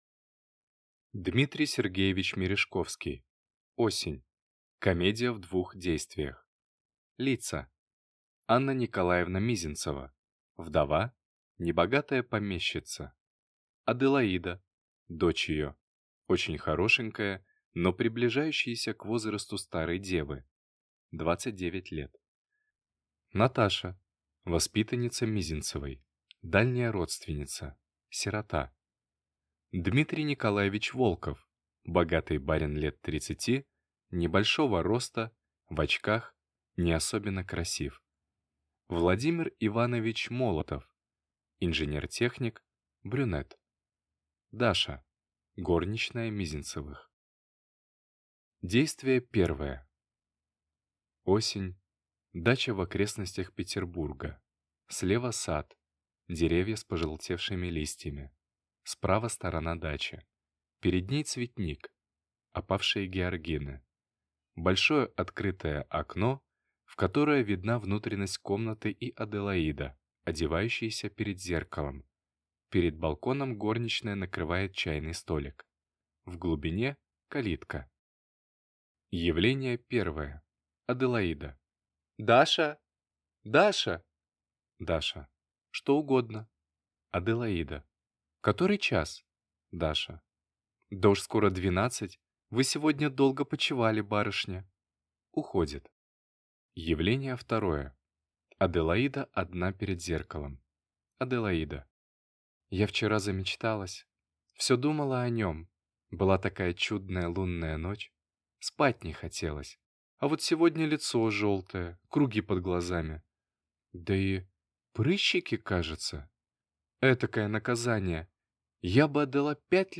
Аудиокнига Осень | Библиотека аудиокниг